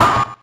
mech_hurt_3.ogg